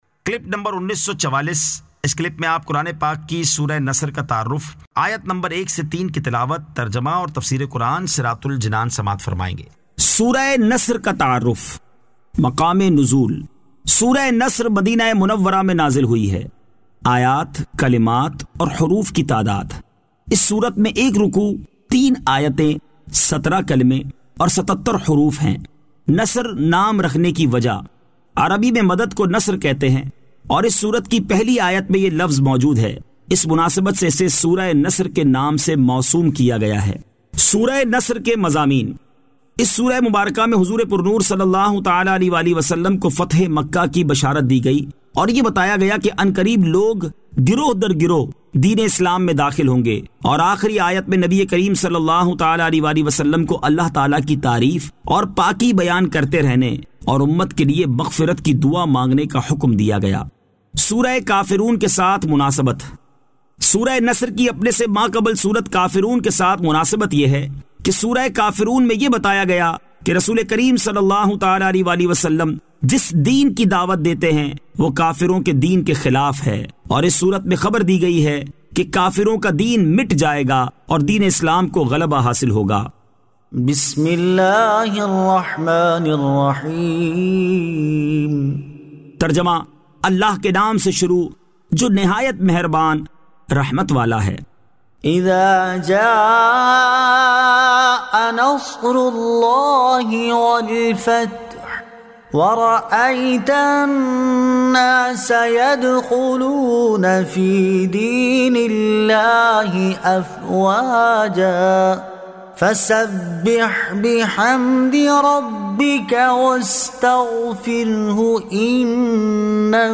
Surah An-Nasr 01 To 06 Tilawat , Tarjama , Tafseer